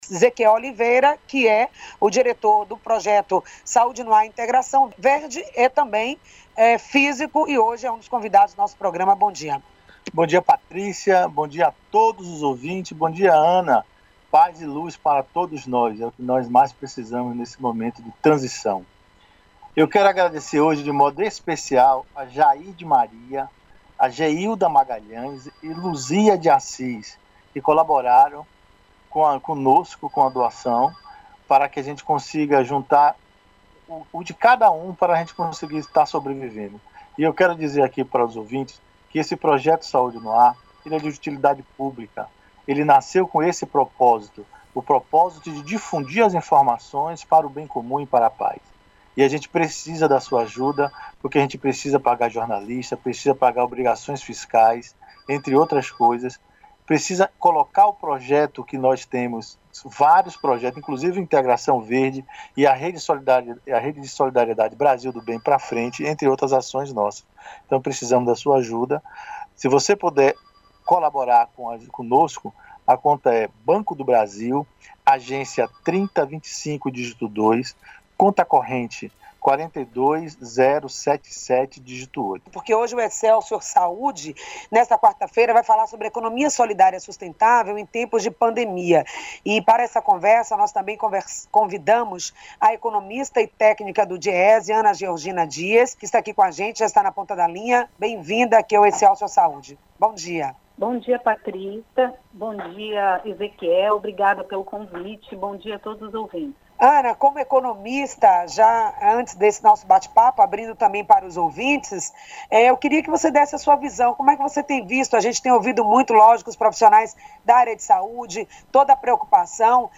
No programa Excelsior Saúde desta quarta-feira (20/05), da Rádio Excelsior da Bahia